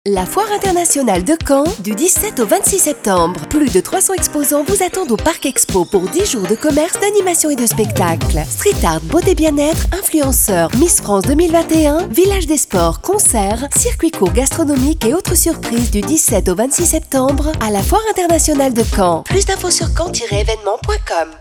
SPOT RADIO FIC 2021
RADIO-FOIRE-DE-CAEN.mp3